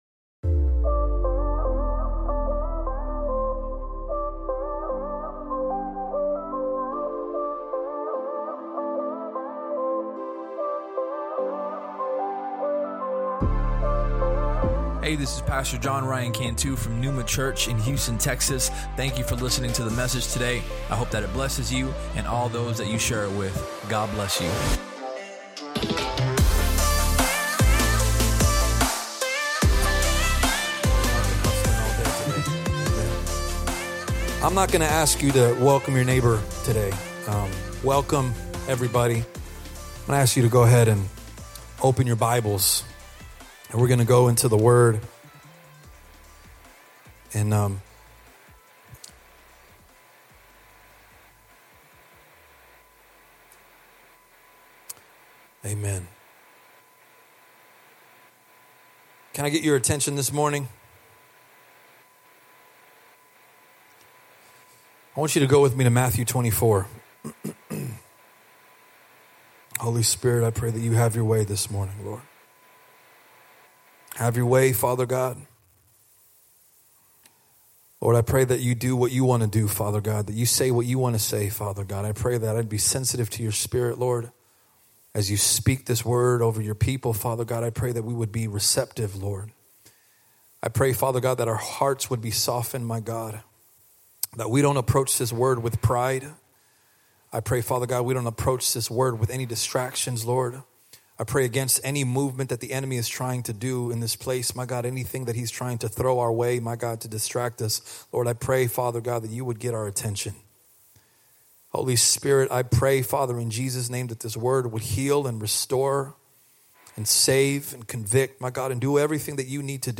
Sermon Topics: Readiness, Stewardship, Urgency